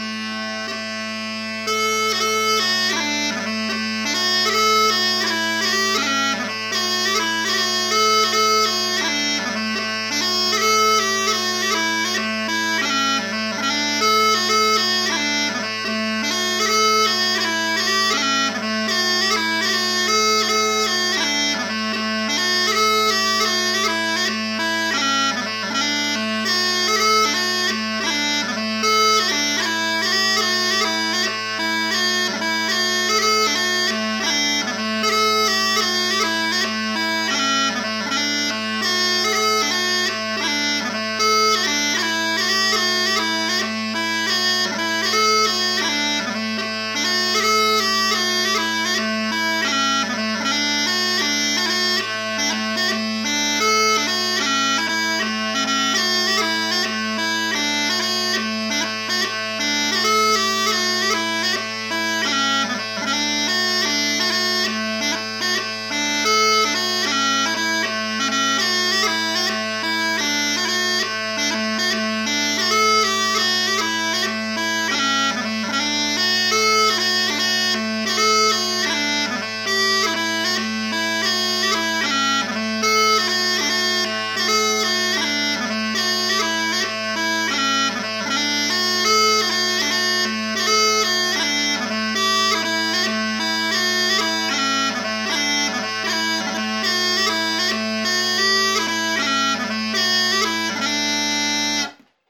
Category: March Tag: 6/8